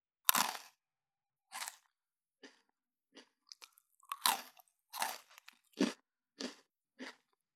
20.スナック菓子・咀嚼音【無料効果音】
ASMR